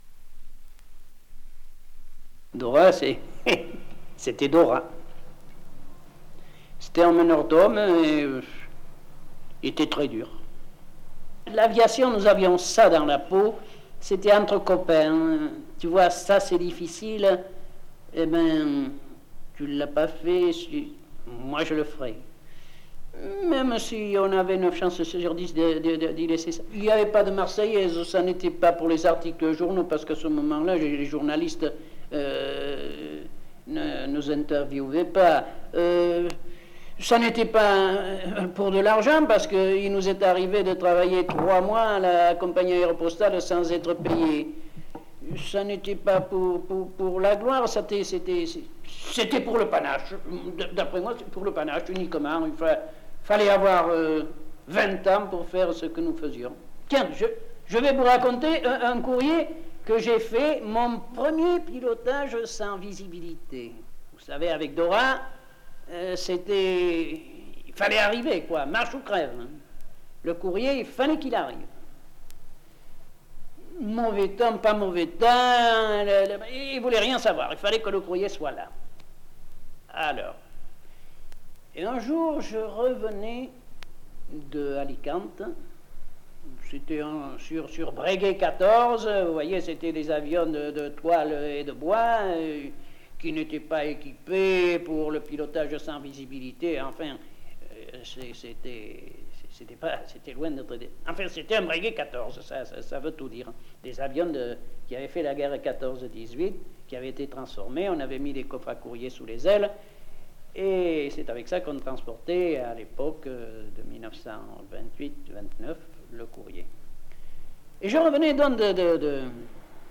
Cet enregistrement est constitué par les récits de onze témoins qui vécurent entre 1918 et 1933, la conquête aérienne de l’Atlantique Sud